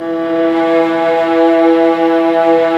Index of /90_sSampleCDs/Roland LCDP13 String Sections/STR_Violas II/STR_Vas4 Amb p